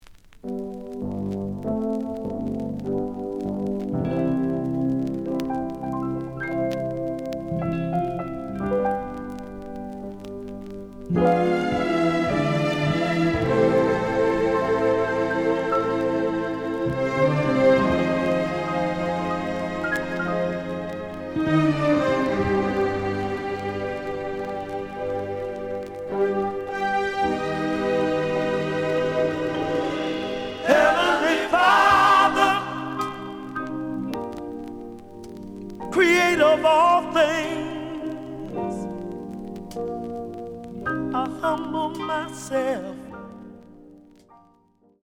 The audio sample is recorded from the actual item.
●Genre: Soul, 70's Soul